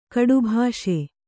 ♪ kaḍubhāṣe